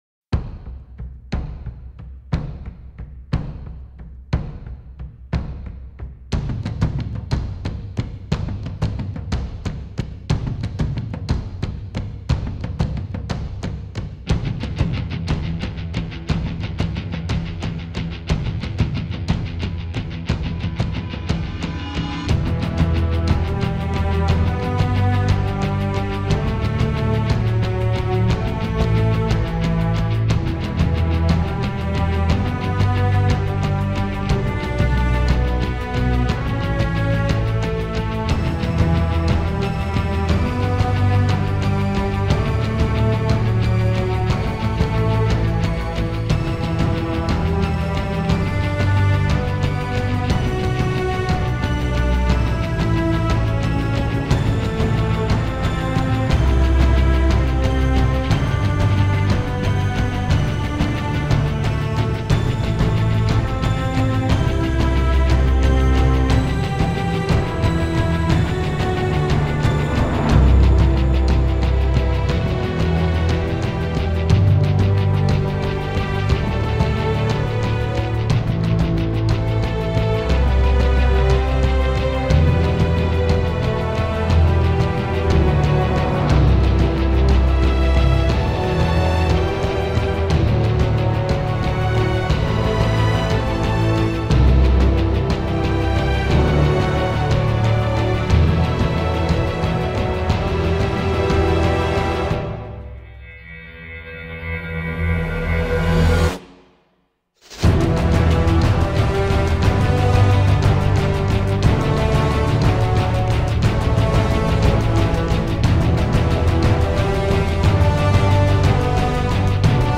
Composição: Instrumental.